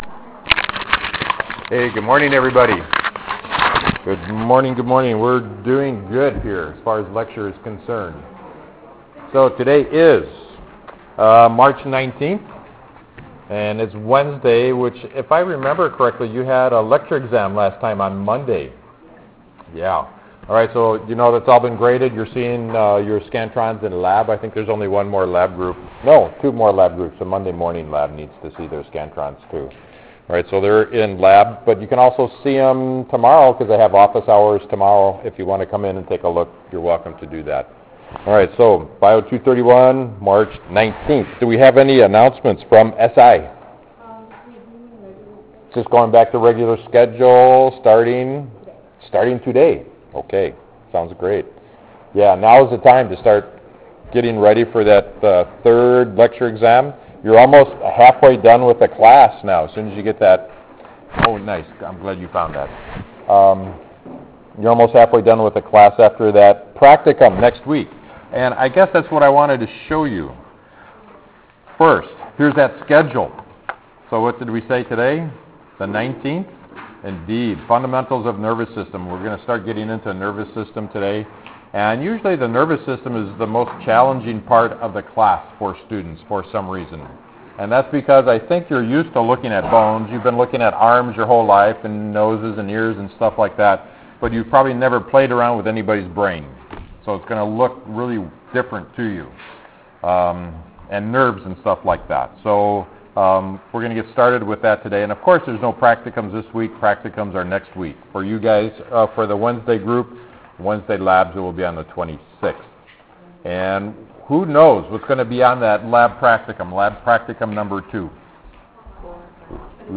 Spring 2015 Hybrid Biol 231, Human Anatomy Lectures